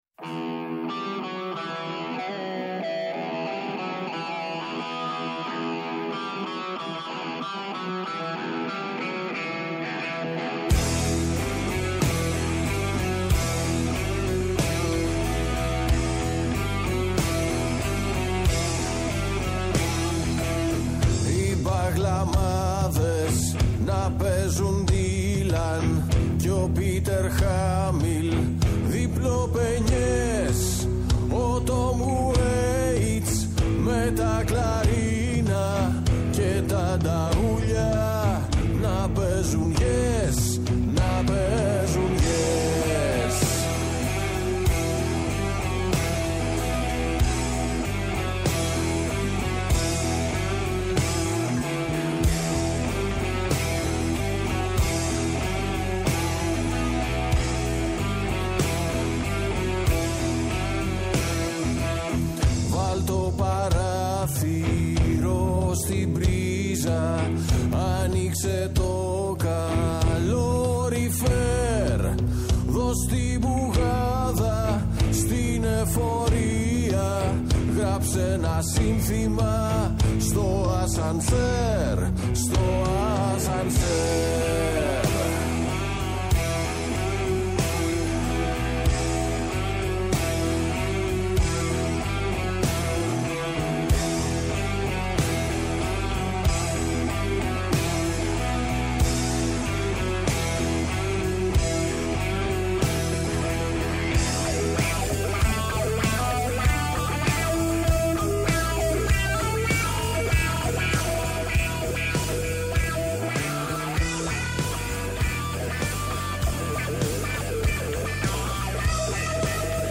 Καλεσμένοι στο στούντιο ο σκηνοθέτης ή οι συντελεστές του ντοκιμαντέρ, μας μιλούν για την ταινία τους, ενώ ακούγονται αποσπάσματα της ταινίας και οι μουσικές της.